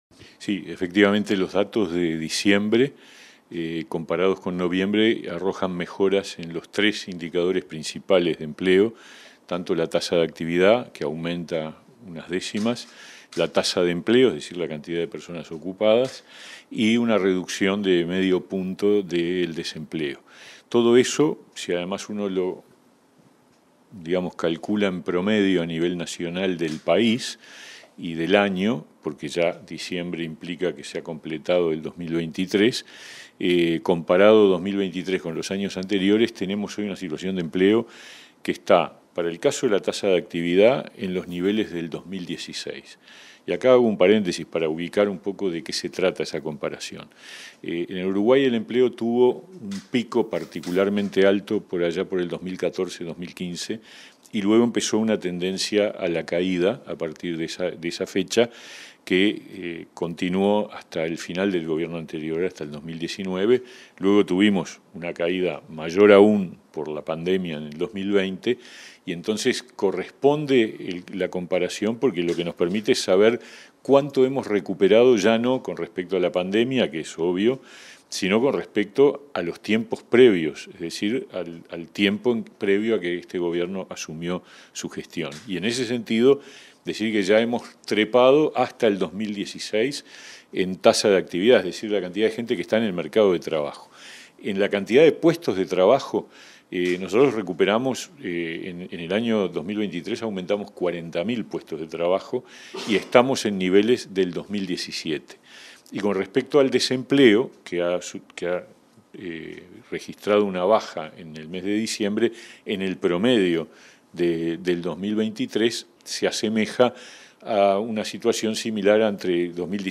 Entrevista al ministro de Trabajo y Seguridad Social, Pablo Mieres